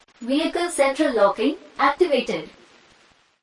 Tesla Lock Sound English Indian Woman
English female voice saying
with an indian accent
(This is a lofi preview version. The downloadable version will be in full quality)
JM_Tesla-Lock_English-IND_Woman_Watermark.mp3